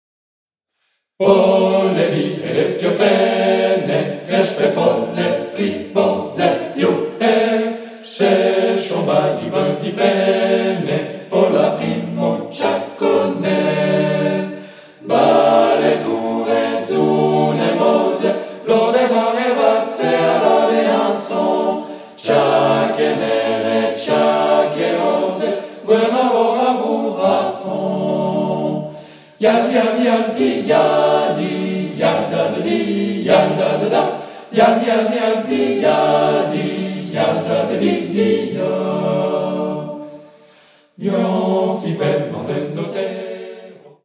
Octuor d’hommes